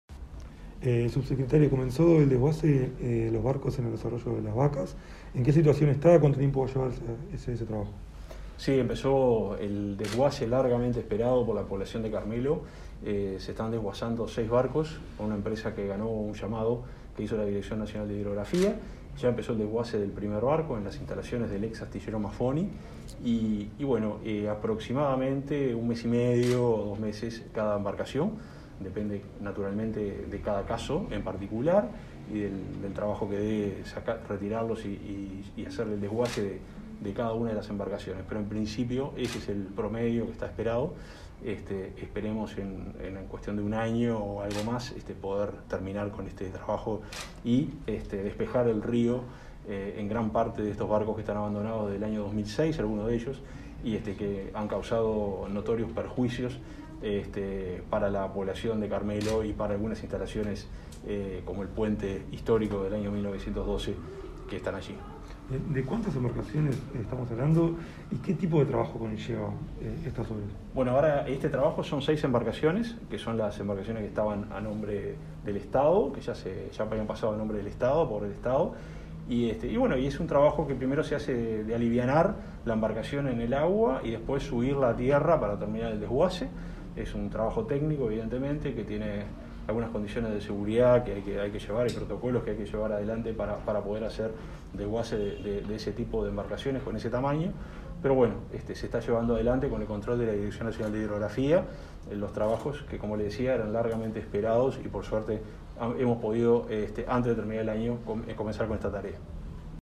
Entrevista a Juan José Olaizola sobre el desguace y retiro de seis barcos del arroyo Las Vacas en Carmelo